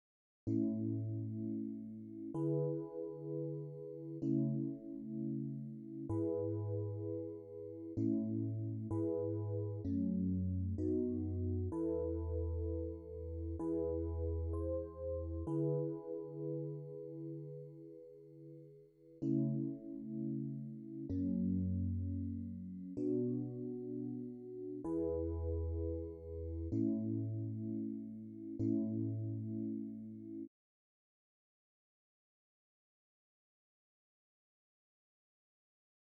19（90ページ）ボイシング　エレピ・２
19_Beethoven-E-Piano-B.wav